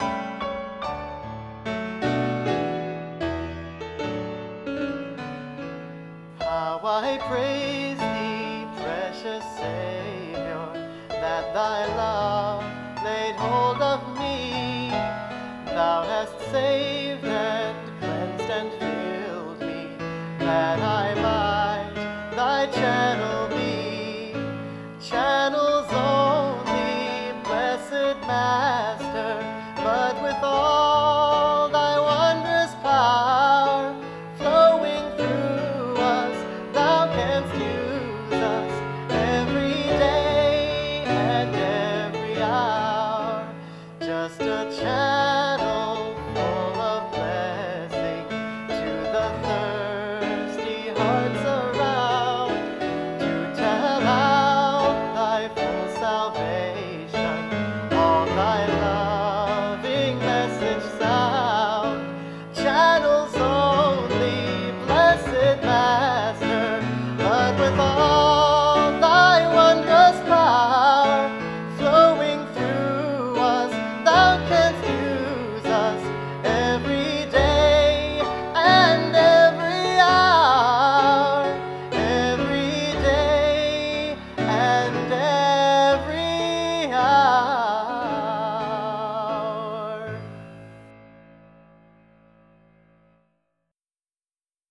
This hymn was written nearly 100 years ago.
I sing this beautiful song with midi piano accompaniment.